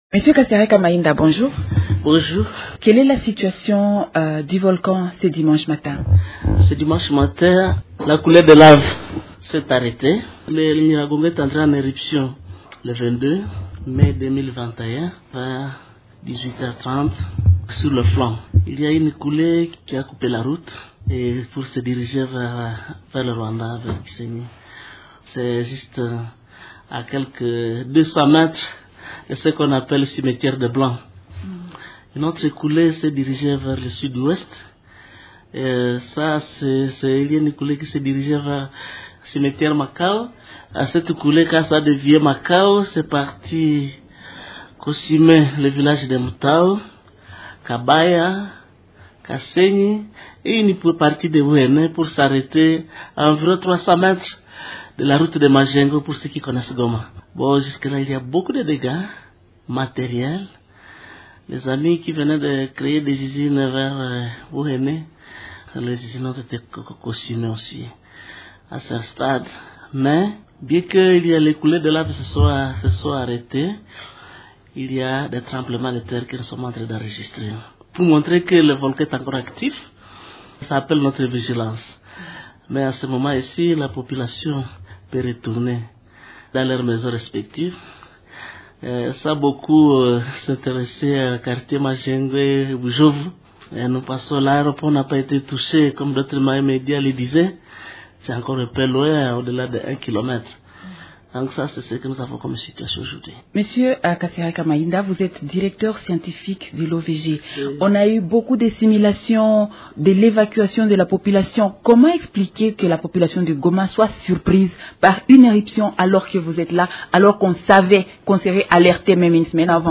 invite_ovg_dimanche_midi.mp3